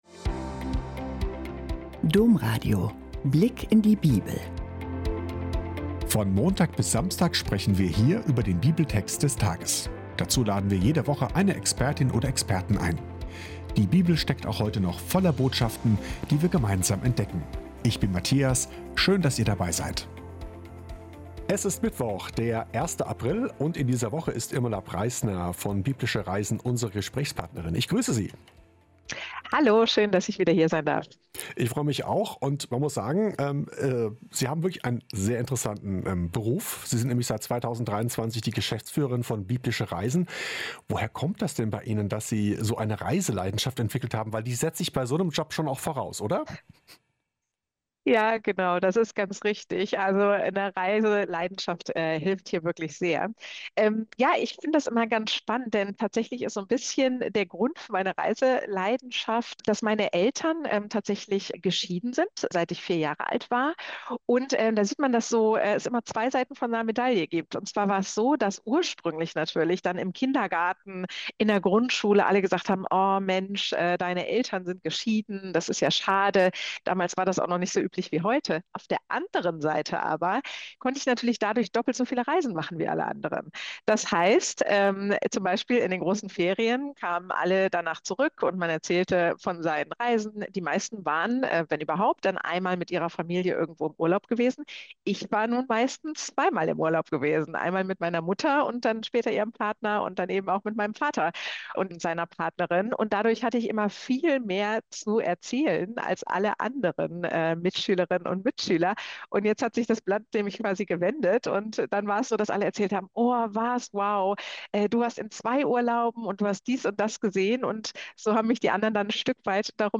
Judas‘ Verrat – warum lässt Jesus das zu? - Gespräch